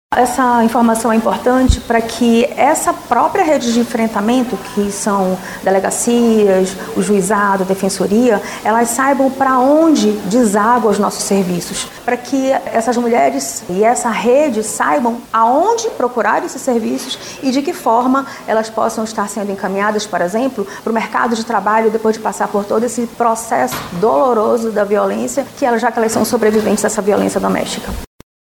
Esta semana, representantes de órgãos e entidades voltados ao atendimento à mulher se reuniu em prol do fluxo de atendimento e políticas aplicadas a este público, como destaca a secretária executiva de Políticas para Mulheres da Sejusc, Syrlan Picanço.